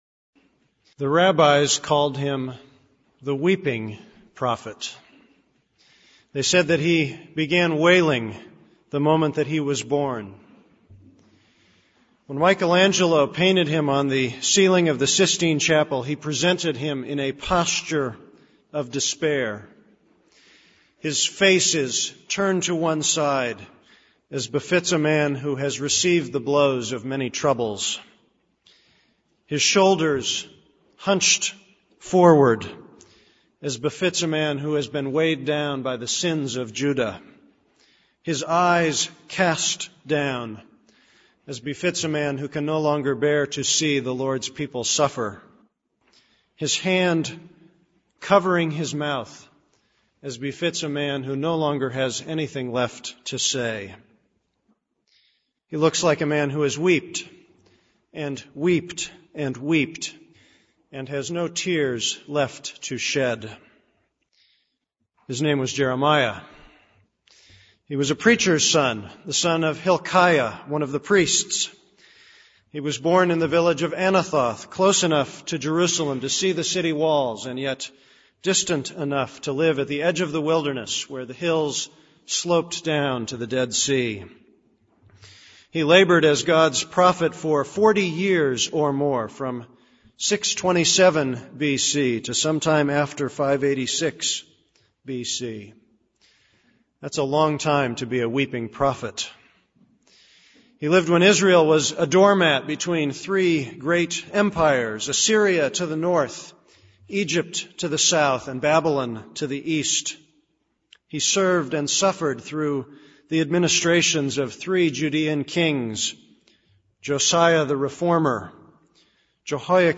This is a sermon on Jeremiah 1:1-10.